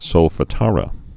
(sōlfə-tärə)